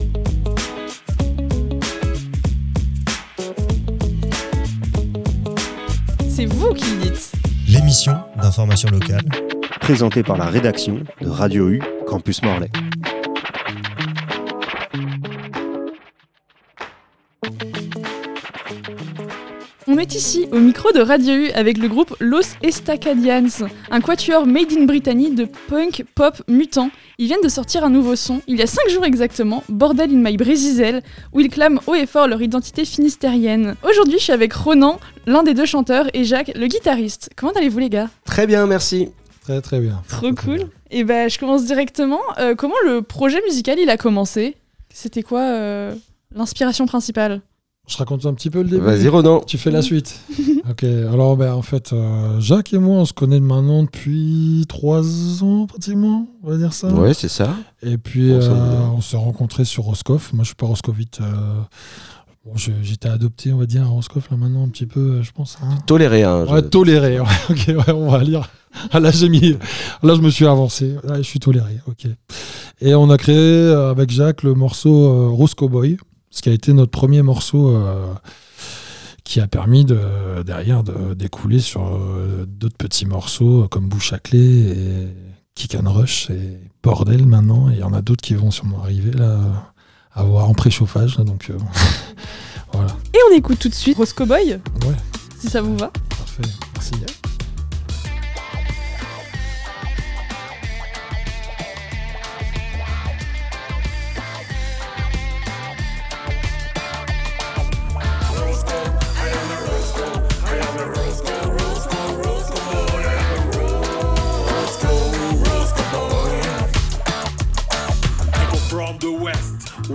Rencontre avec deux membre du groupe Los Estacadians
interview_los_estacadian.mp3